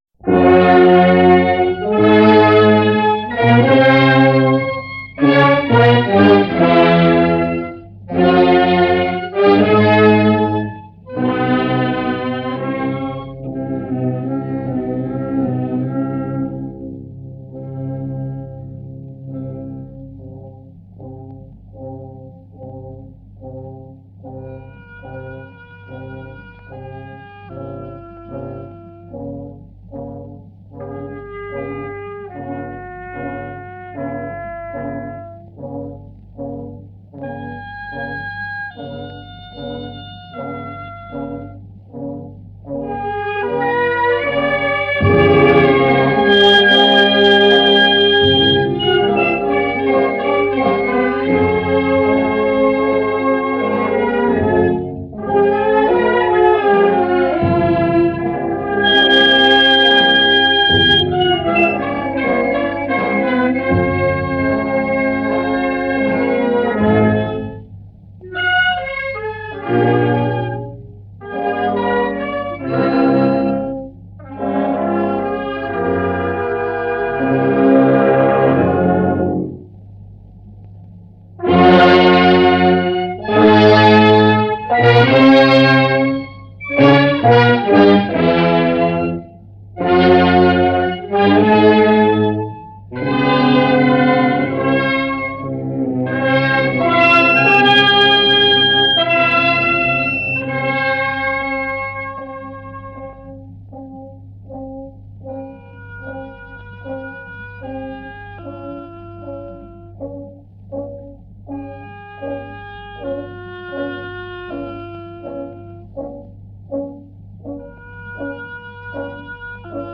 Genre: Classical